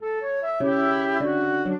flute-harp
minuet6-4.wav